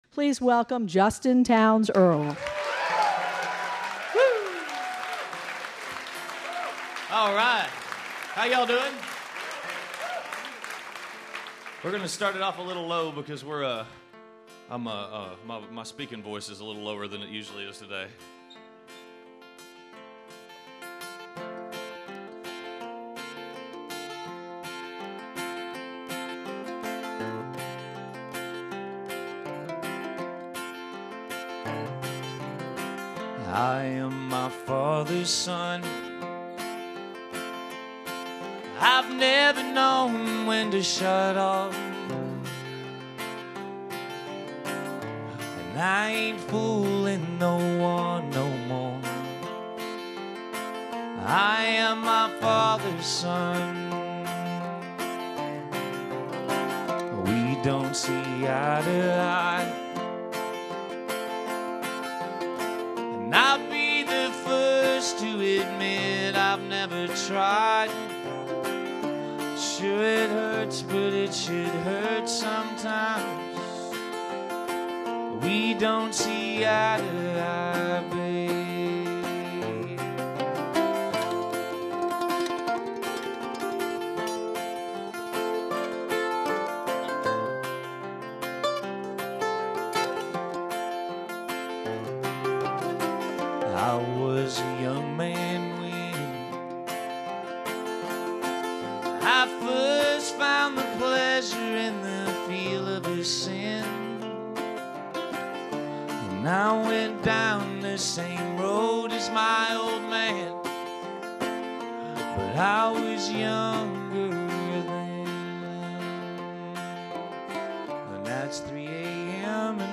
roots music
outlaw-country singer-songwriter
live in concert